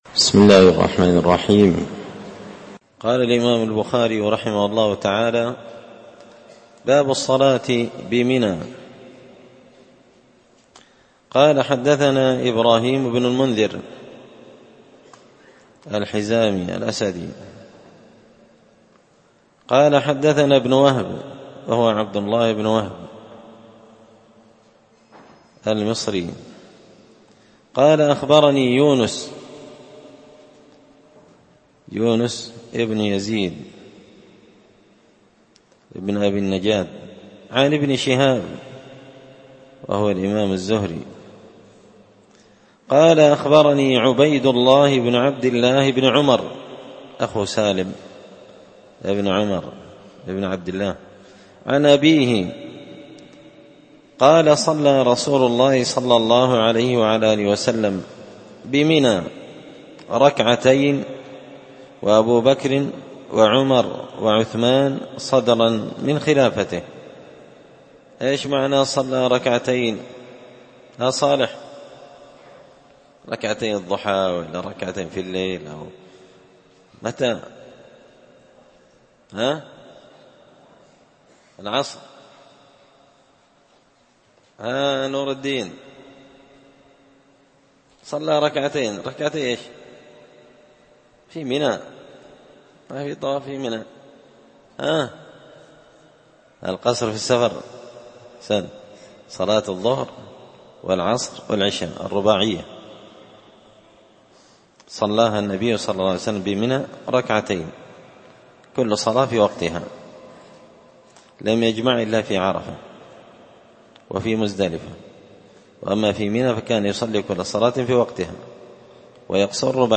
كتاب الحج من شرح صحيح البخاري – الدرس 76